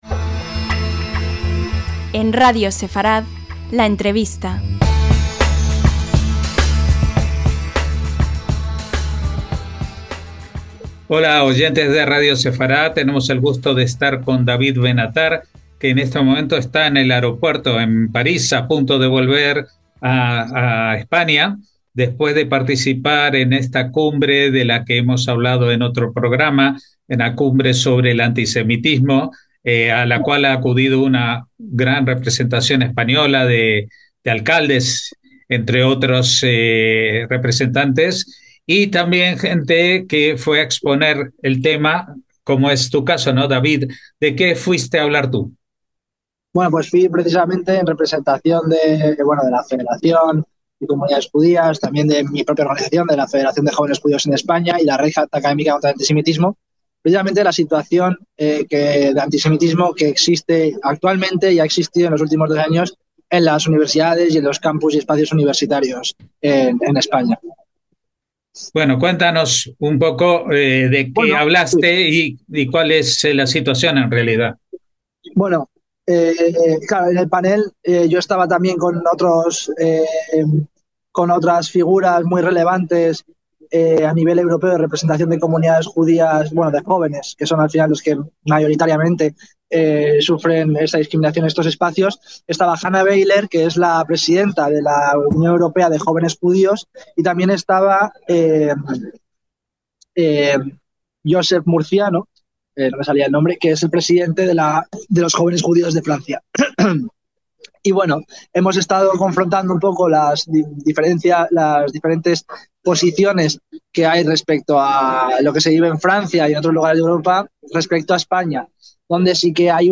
LA ENTREVISTA